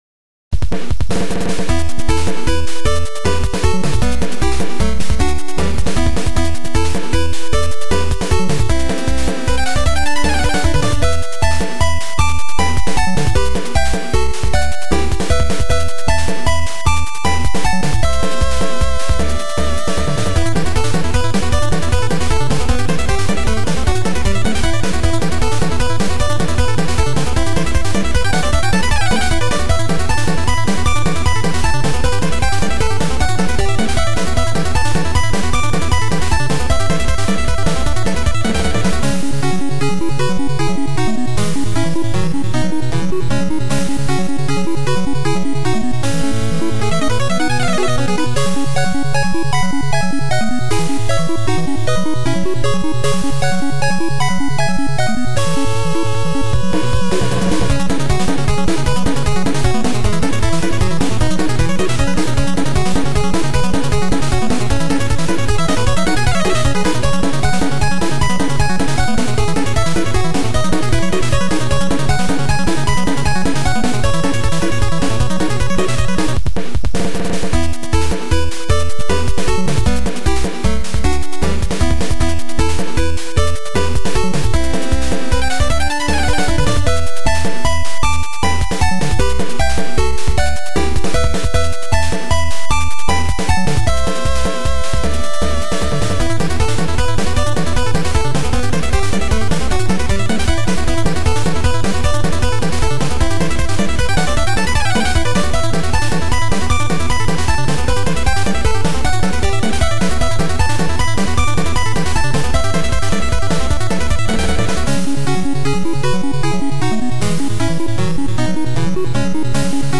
耳コピかなり荒いからアレなんだけど、しばらく手直ししてる余裕もないので